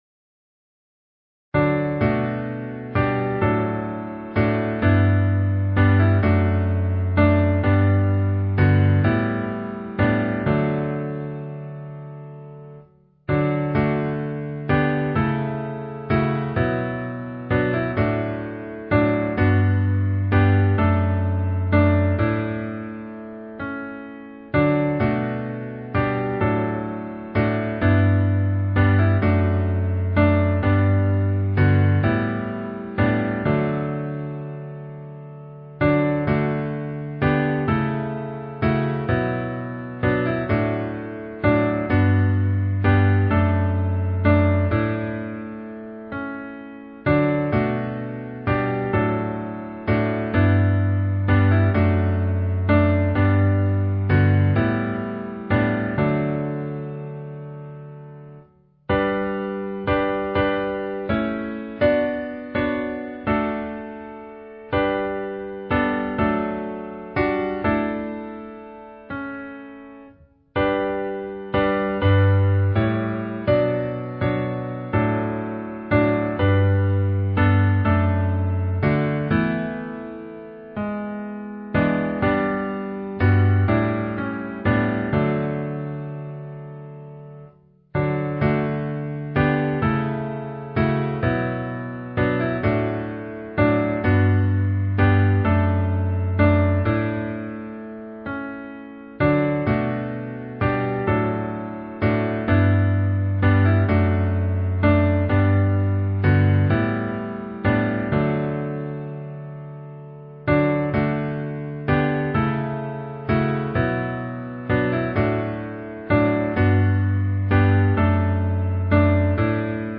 Key: E♭ Meter: 8.6.8.6